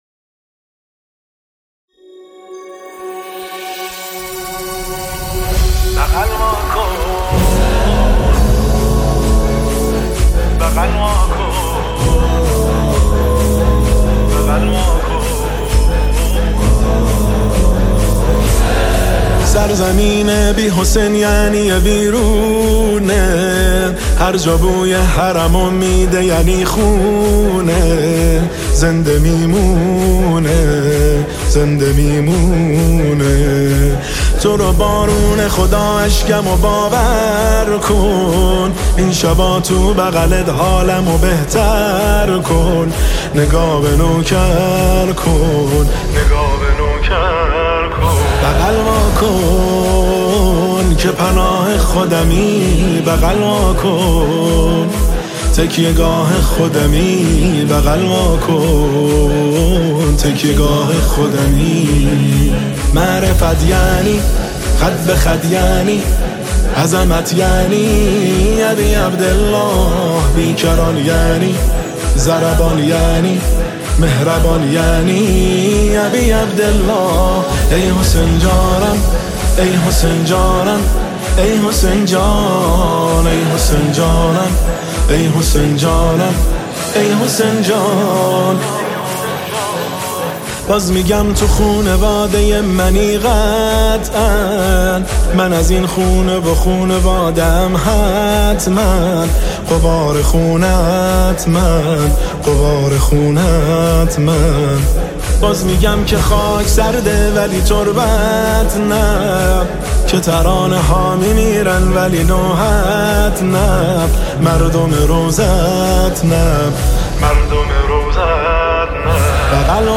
شور شب اول محرم الحرام 1401
هیئت بین الحرمین طهران